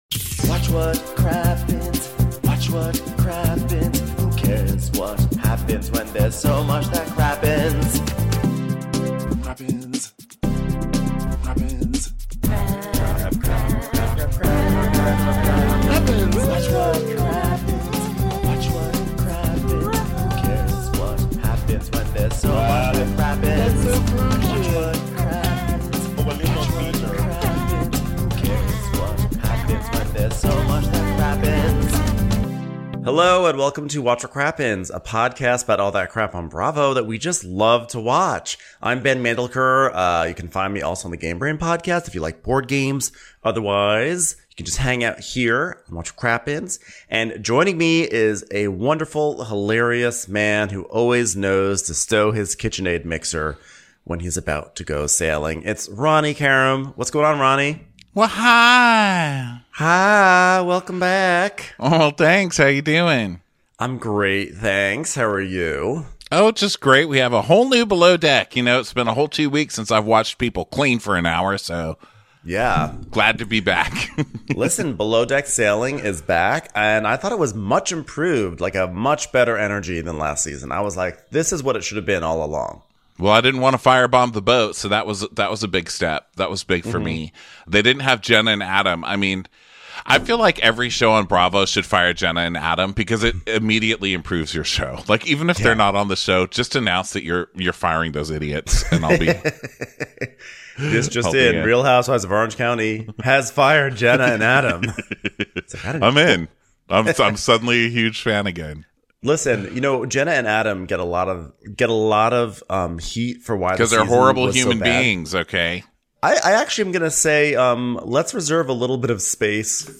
Below Deck Sailing Yacht is back, and we have a whole boat of new accents to butcher!